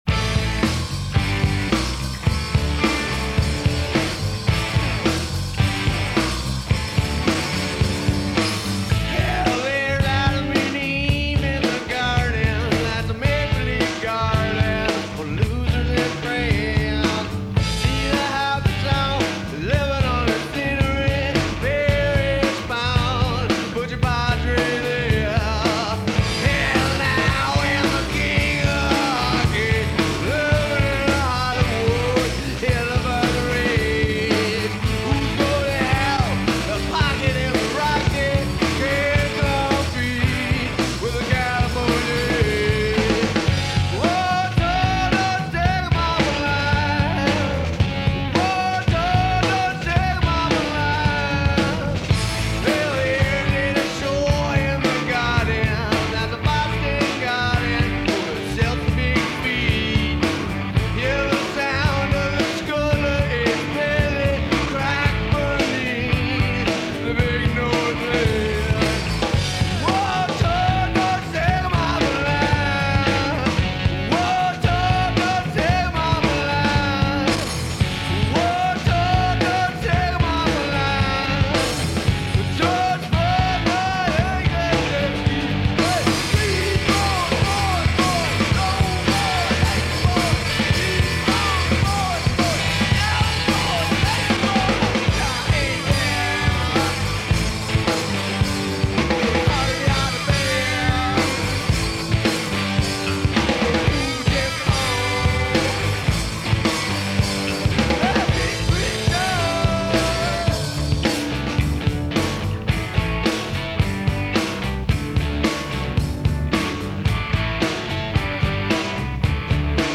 Some of the sound quality is pretty rough.